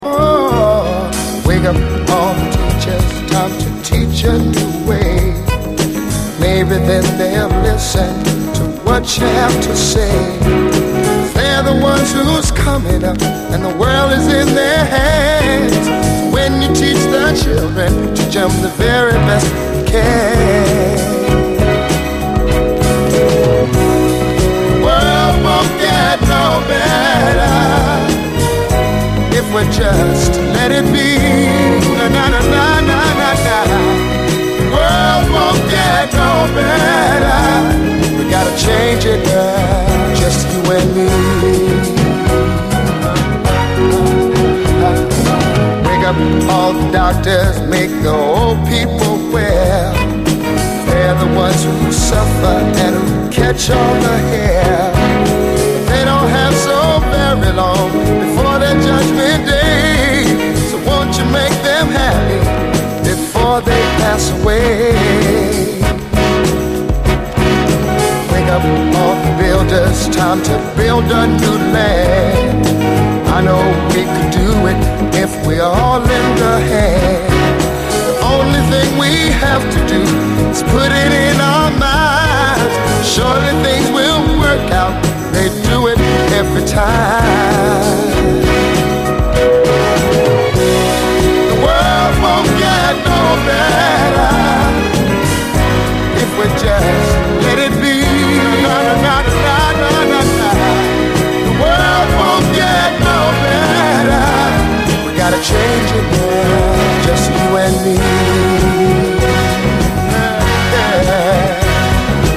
眩しいほどの幸福感が半端ではない黄金の一曲！